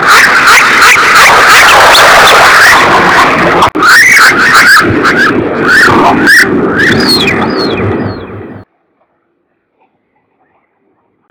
Amazonetta brasiliensis ipecutiri - Pato brasilero